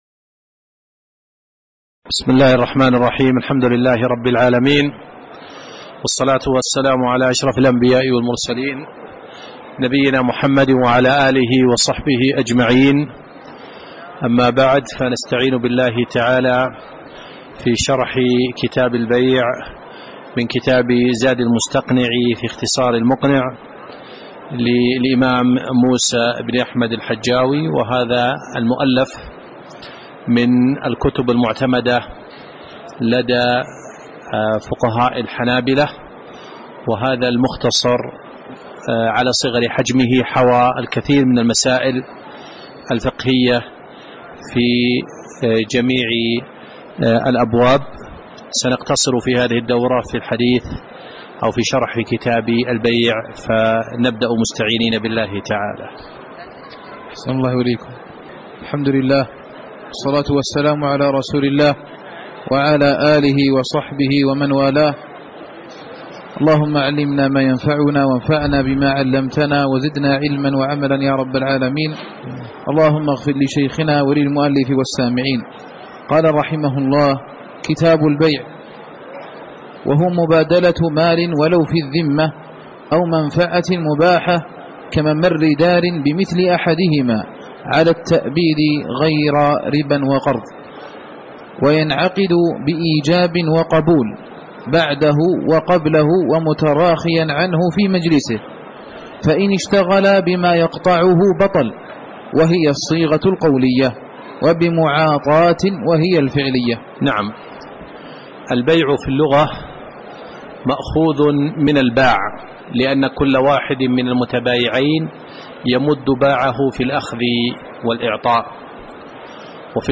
تاريخ النشر ١٠ جمادى الآخرة ١٤٣٨ هـ المكان: المسجد النبوي الشيخ